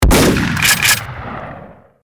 دانلود صدای تفنگ 1 از ساعد نیوز با لینک مستقیم و کیفیت بالا
جلوه های صوتی